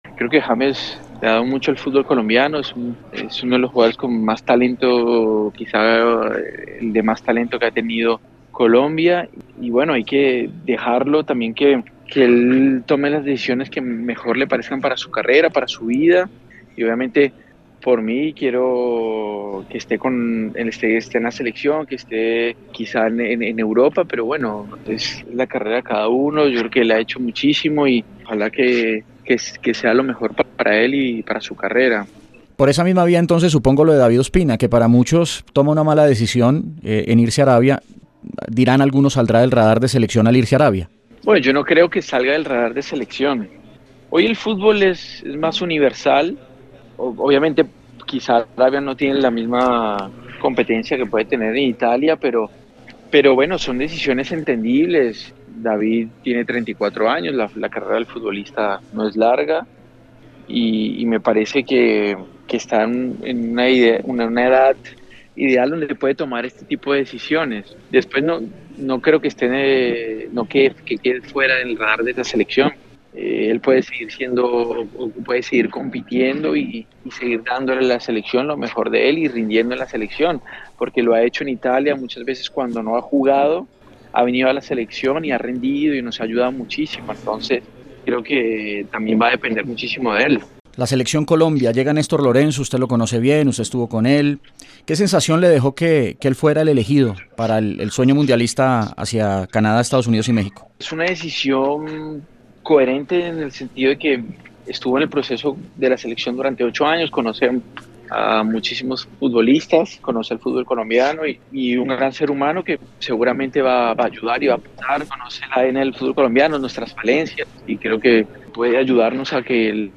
En la parte final de la entrevista, el delantero samario no pudo contener el llanto al recordar a su padre, Radamel García, quien falleció en enero del 2019.